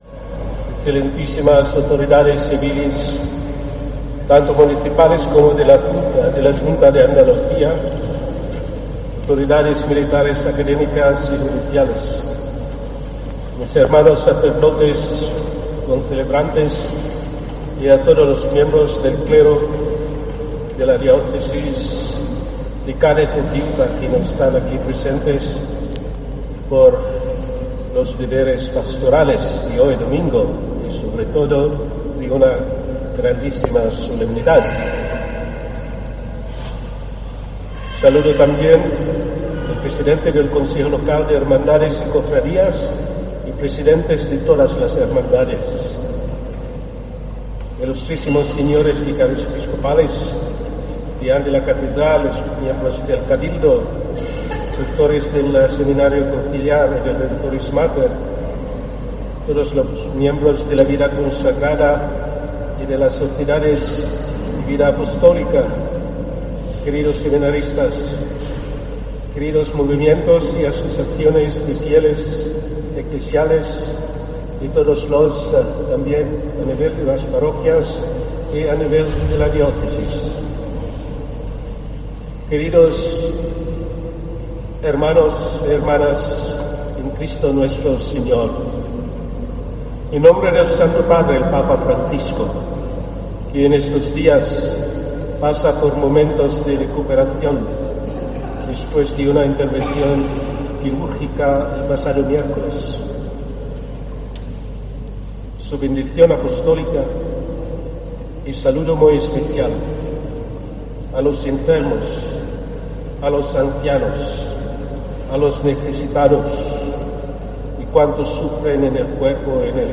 Homilía del nuncio del Papa, Bernardito Auza, en Cádiz con motivo del Corpus Christi 2023